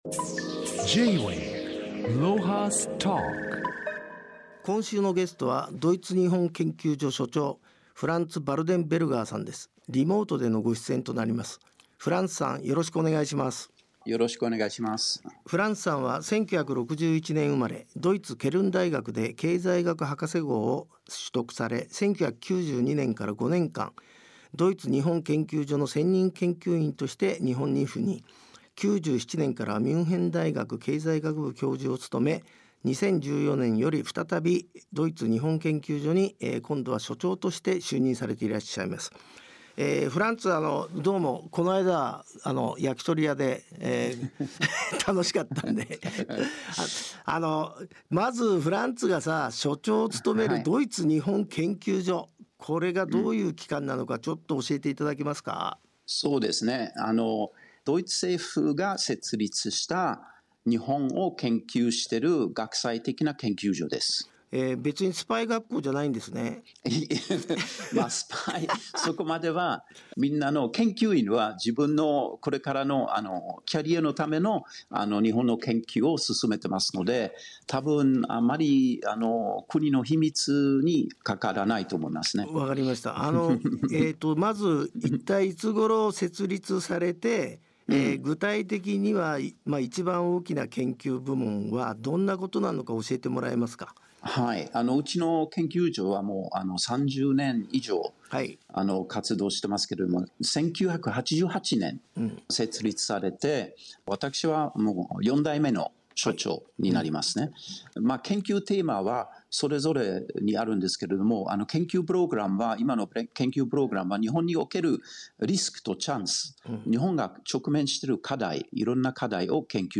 interviewed on Japanese radio